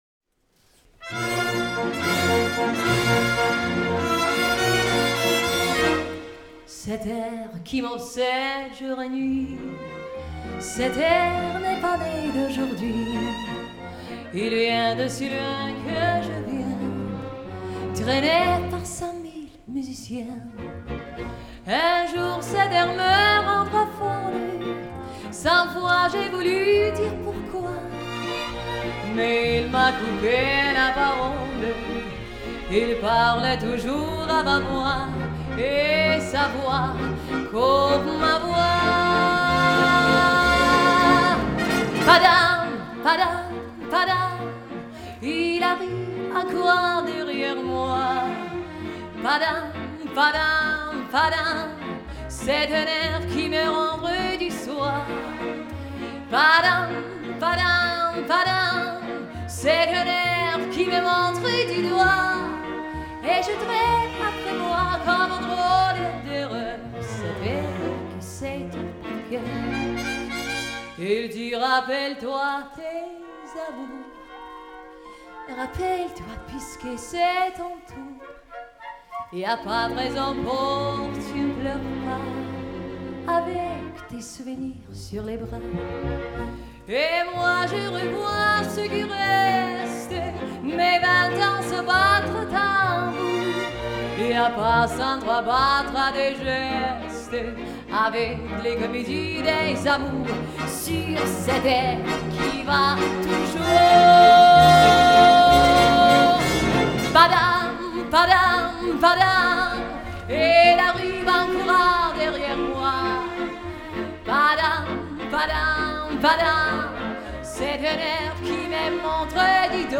Populārā mūzika
Aplausi !
Siguldas estrāde